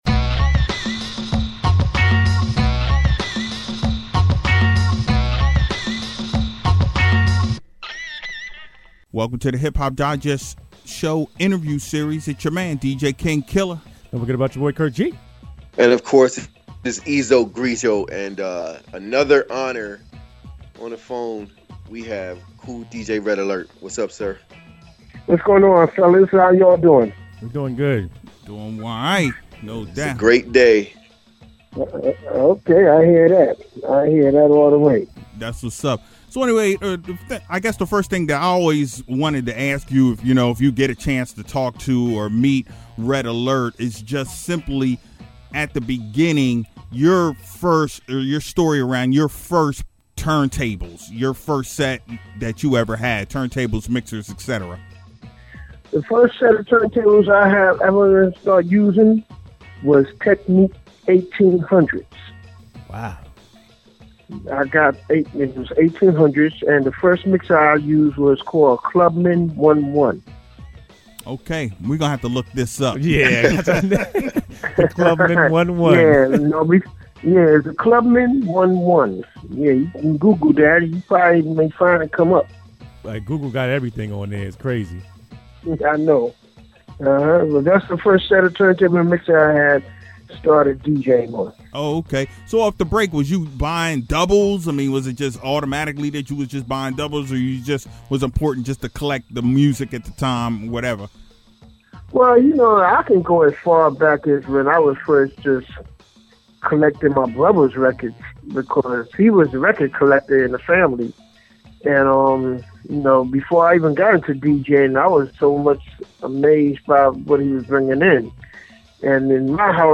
Hip-Hop Digest Show – Red Alert Interview